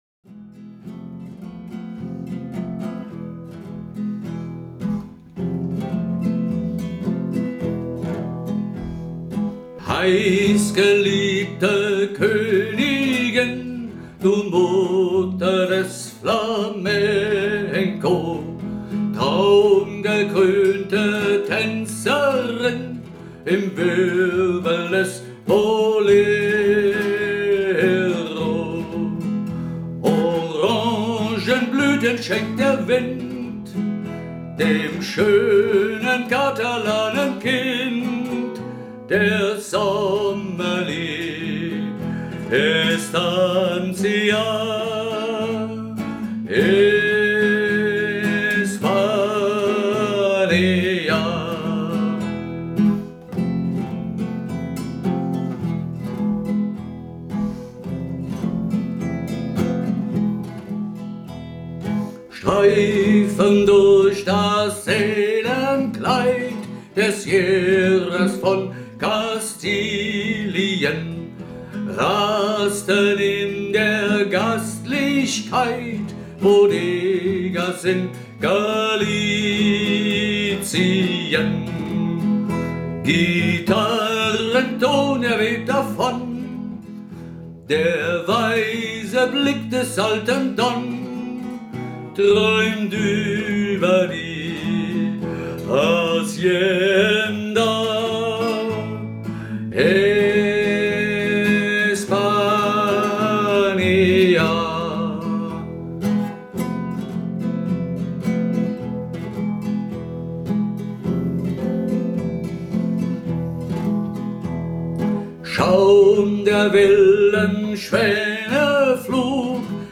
solo_lied-der-spanienfahrer_voll.mp3